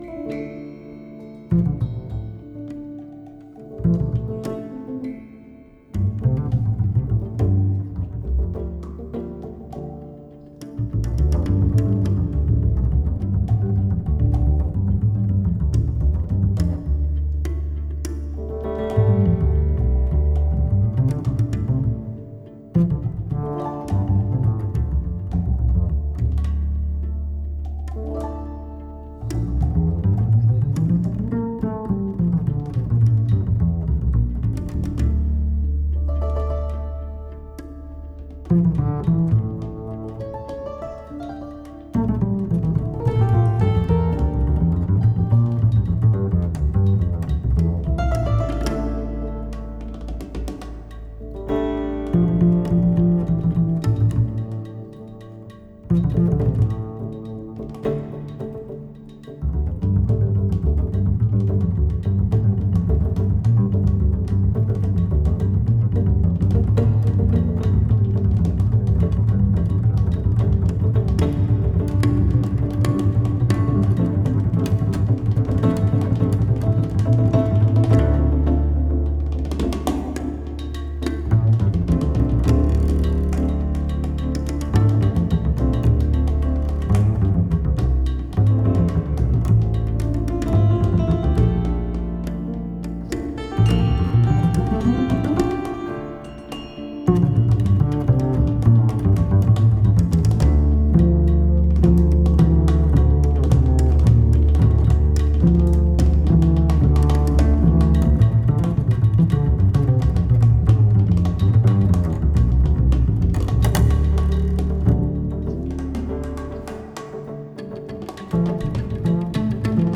کمانچه
فورته پیانو و هارمونیوم
ویولونه و کنترباس
سازهای کوبه‌ای